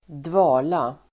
Ladda ner uttalet
Uttal: [²dv'a:la]